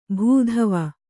♪ bhū dhava